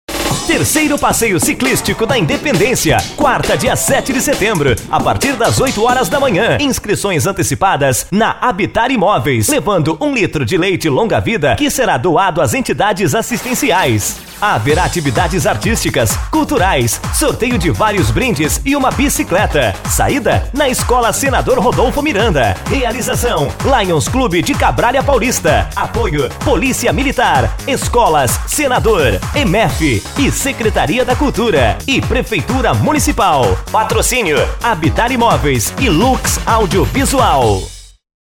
CLIQUE AQUI E FA�A O DOWNLOAD DO SPOT DE R�DIO DO PASSEIO CICLISTICO DO LIONS CLUBE DE CABR�LIA PAULISTA